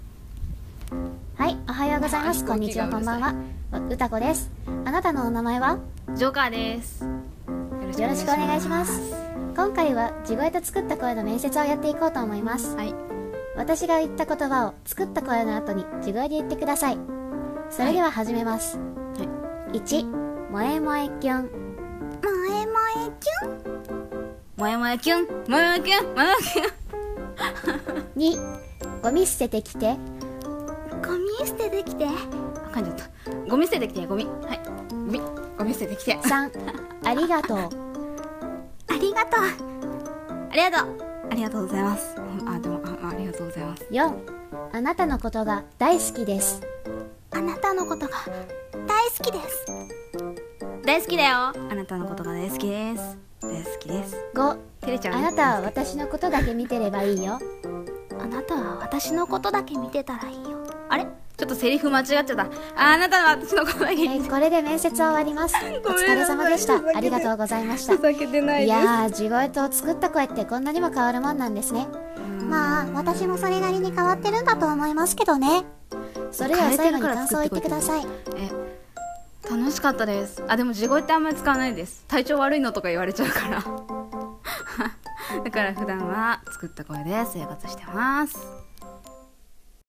声面接【地声と作った声】